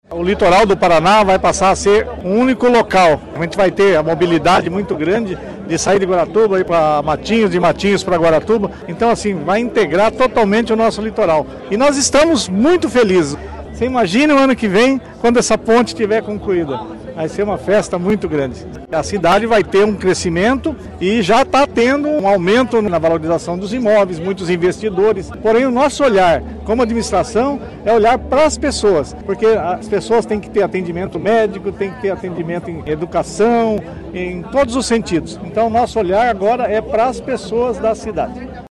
Maurício Lense (Pode), prefeito de Guaratuba, afirmou que a obra vai mudar não só a economia da cidade, mas todo o Litoral do estado.